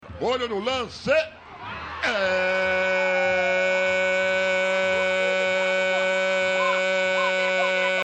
silvio-luiz-narrando-varios-gols-pela-copa-paulista-rede-tv-1.mp3